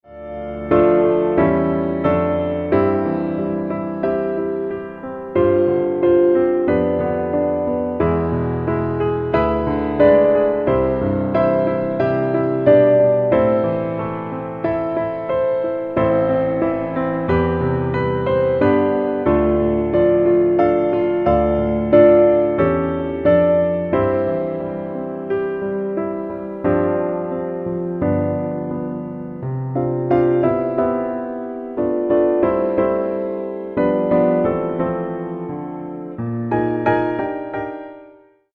Piano - Low